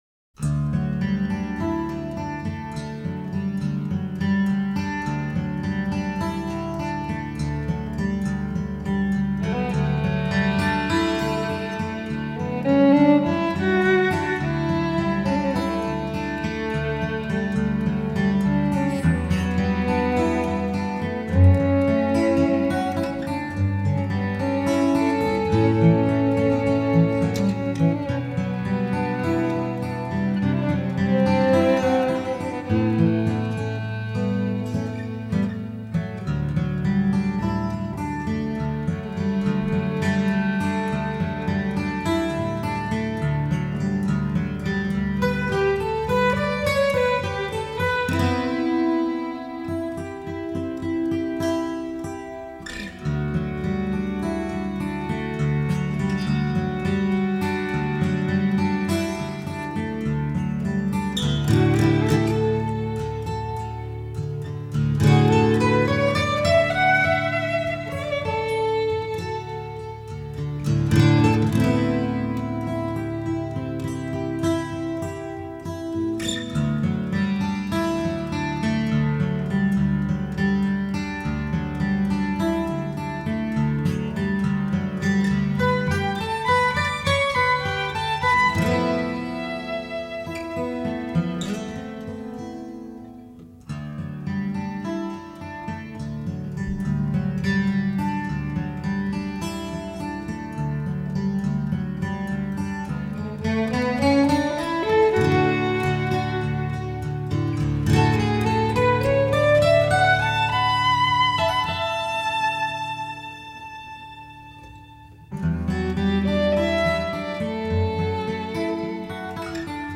Guitar, Composer
Violin
Soprano & Alto Sax
Electric Bass
Drums, Percussion